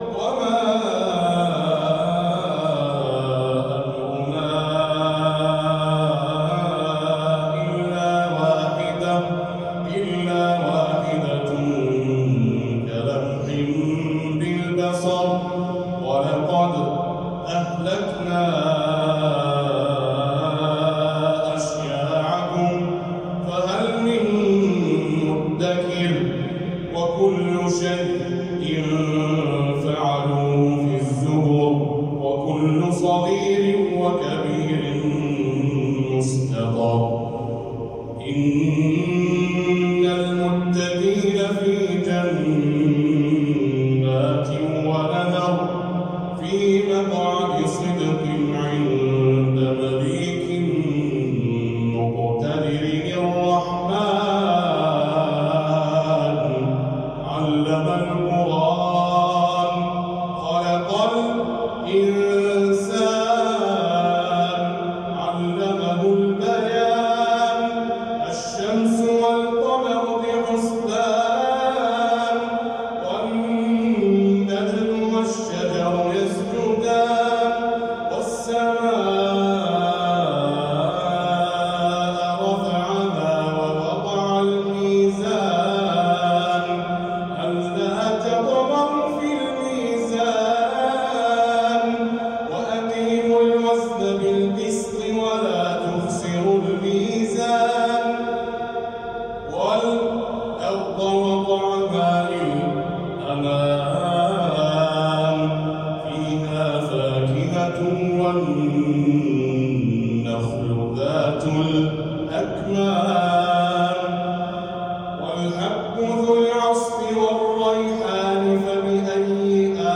تلاوة من سورتي القمر والرحمن (برواية خلف عن حمزة) ومما تمتاز به رواية خلف عن حمزة: حذف البسملة بين السور القارئ